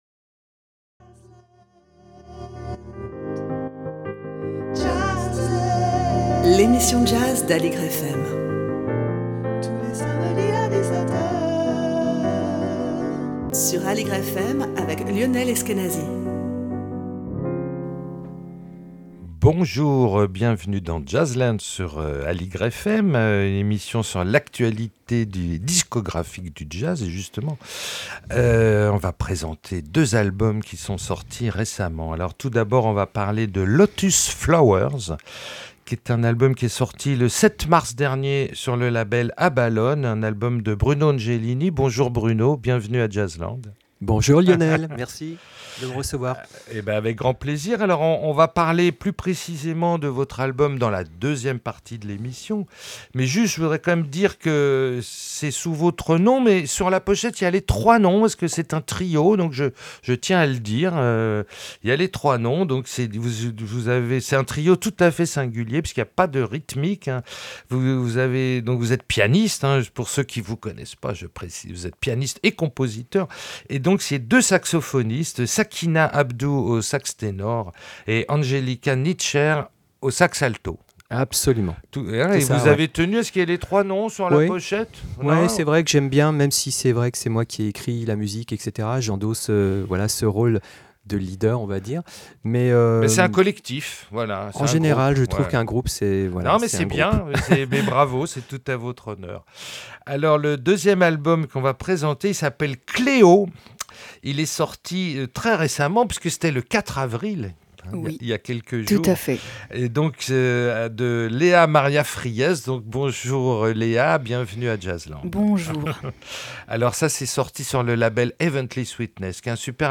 sur l'actualité du jazz avec deux invités